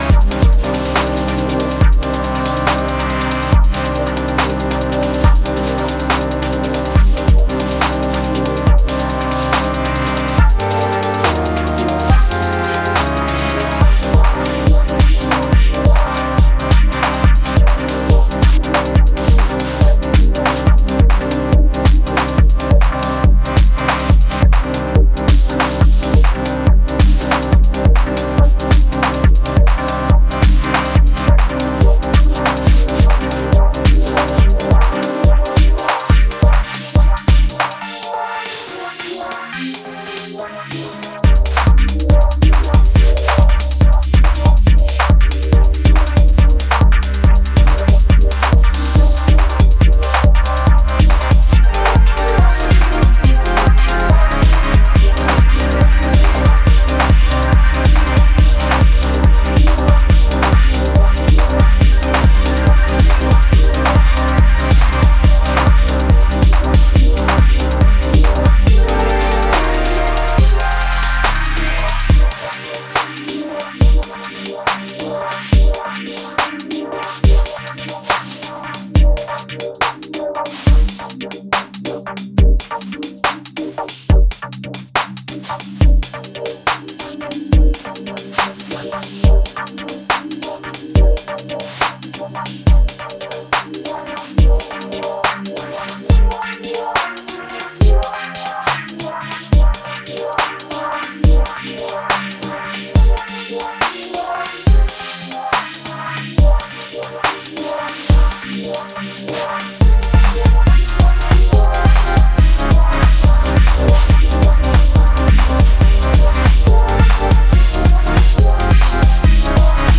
ダブステップにジャージークラブを掛け合わせたようなビートが鮮烈な印象の
キャッチーなフックも満載のポスト・ベース最前線です。